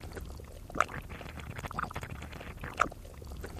Mud Blurps Loop